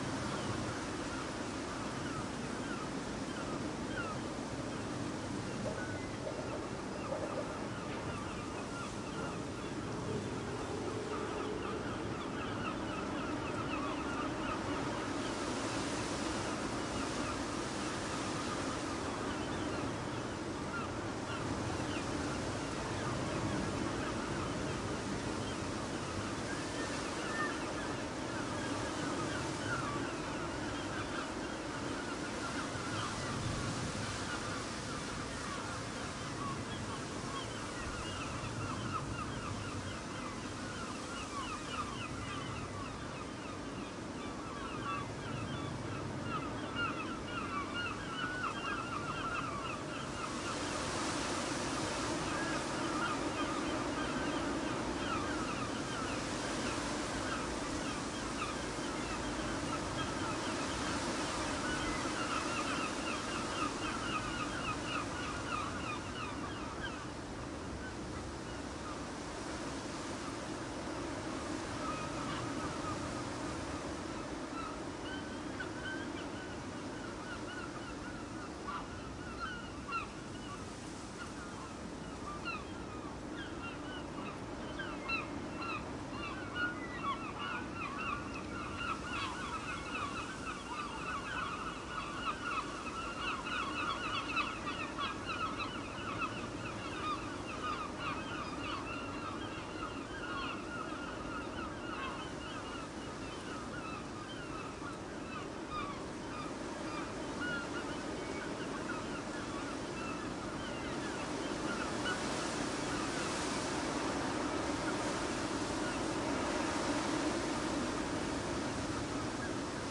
风 - 声音 - 淘声网 - 免费音效素材资源|视频游戏配乐下载
描述：吹在树的风在庭院里。喧嚣的天气。你可以听到地板周围的树叶等。
标签： 天气 大风 大风 树木 阵风 大风
声道立体声